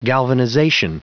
Prononciation du mot galvanization en anglais (fichier audio)
Prononciation du mot : galvanization